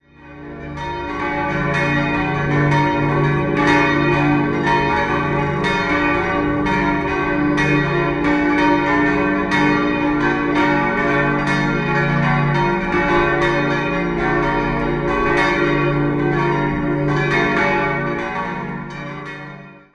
Schwandorf, Pfarrkirche Herz Jesu Die Pfarrkirche Herz Jesu steht im Süden von Schwandorf, im so genannten "Lindenviertel". In den Jahren 1950/51 wurde sie nach den Plänen des Regierungsbaumeisters Hans Beckers errichtet. 1952 schließlich wurde Herz Jesu zur eigenen Pfarrei erhoben. 6-stimmiges Geläut: d'-f'-g'-b'-d''-f'' Die fünf größeren Glocken wurden 1958/59 von der Gießerei Hofweber in Regensburg gegossen.